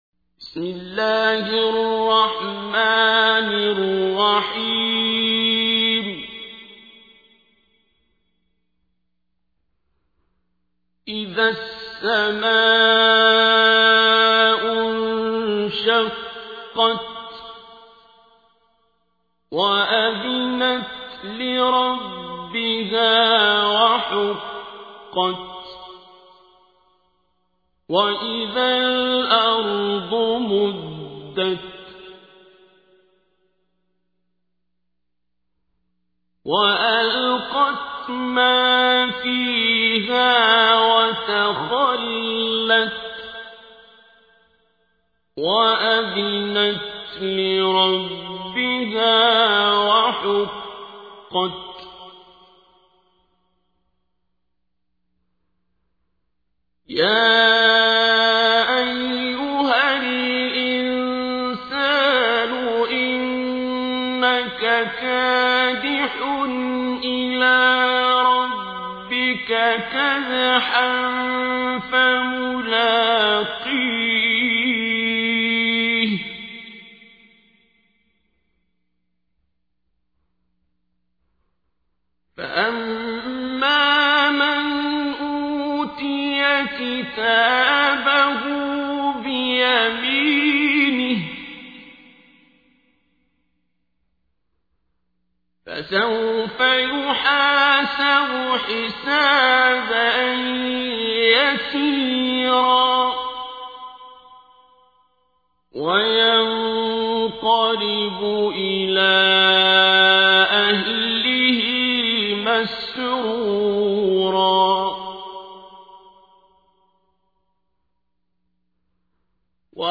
تحميل : 84. سورة الانشقاق / القارئ عبد الباسط عبد الصمد / القرآن الكريم / موقع يا حسين